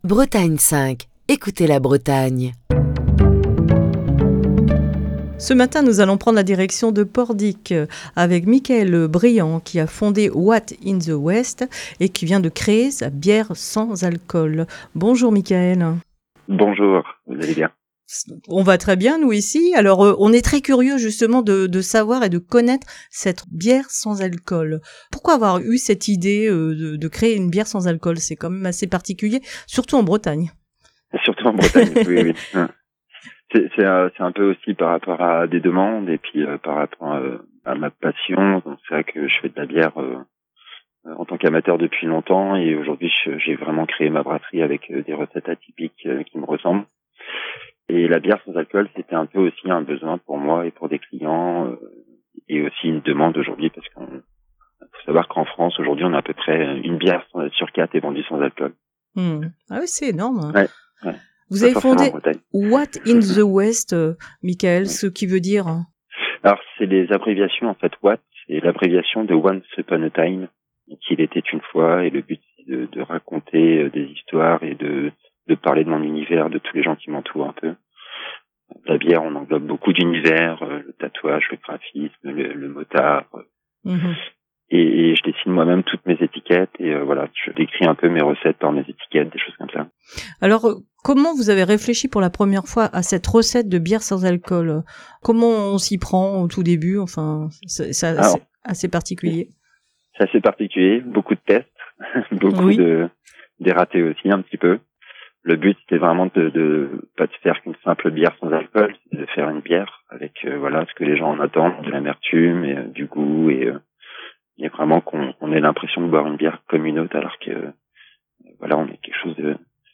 Aujourd'hui dans le coup de fil du matin